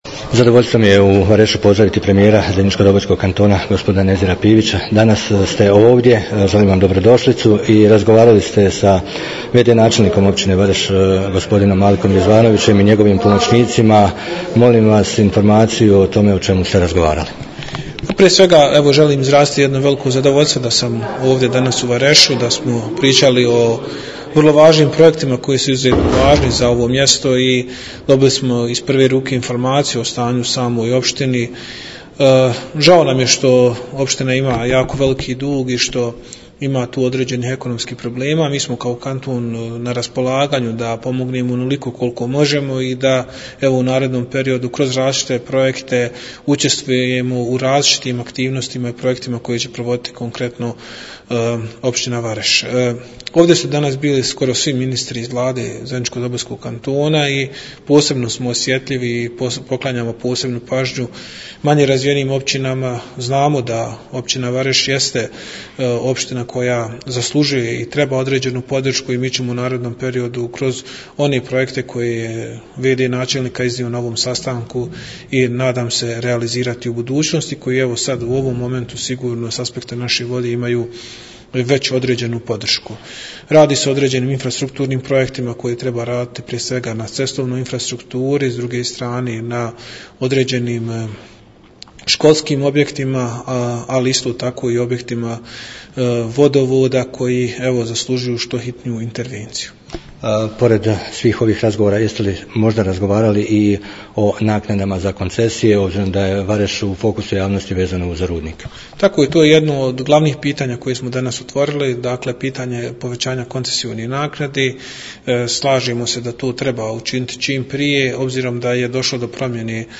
Nakon završenog sastanka poslušajte izjave za radio Bobovac premijera Nezira Pivića i v.d. načelnika Malika Rizvanovića.